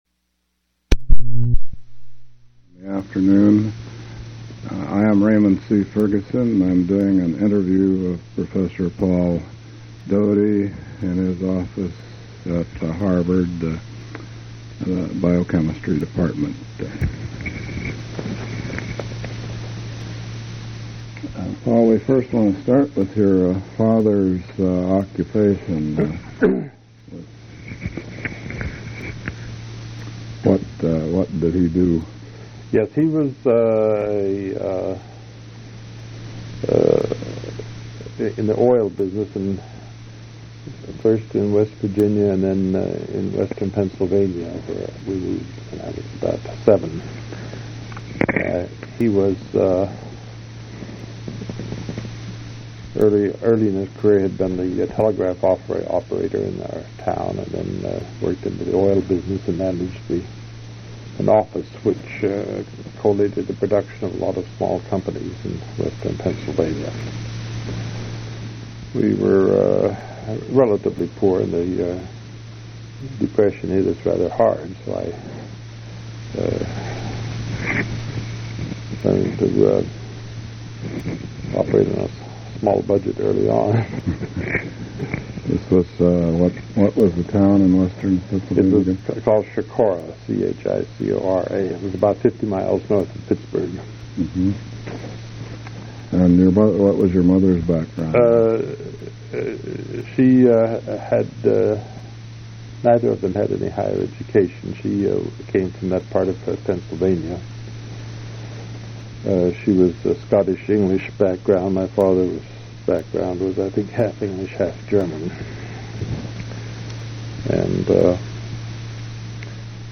Oral history interview with Paul M. Doty
Place of interview Harvard University Massachusetts--Cambridge